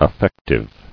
[af·fec·tive]